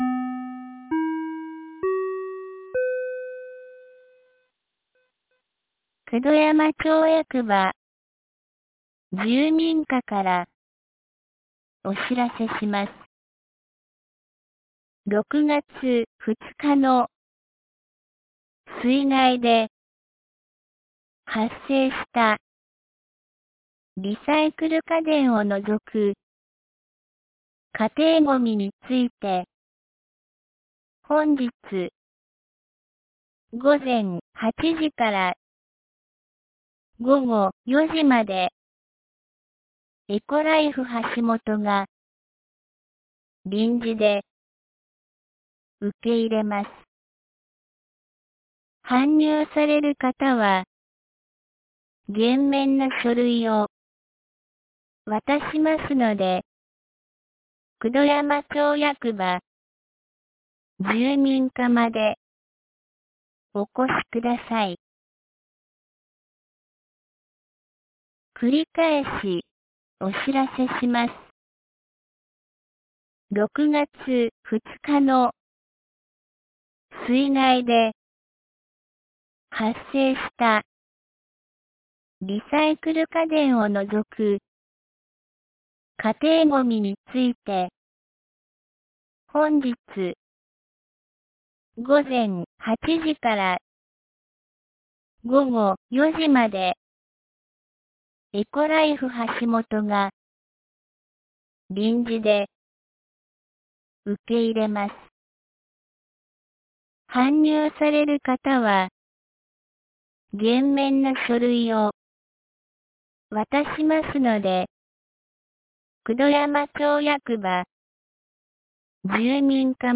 2023年06月04日 09時02分に、九度山町より全地区へ放送がありました。
放送音声